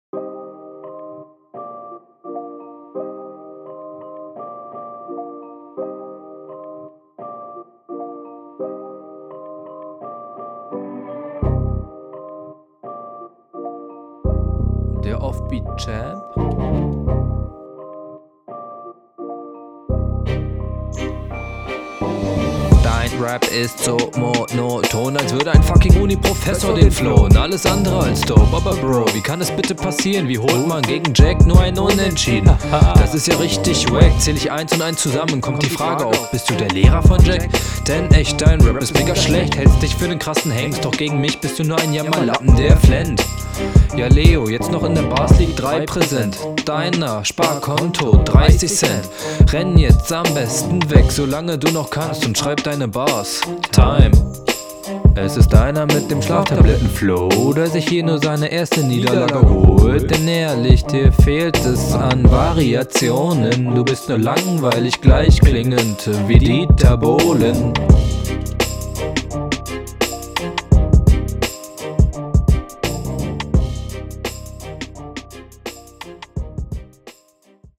Reime komplett absturz... wärst du mehr on beat wäre die Runde aber wahrscheinlich hörbar.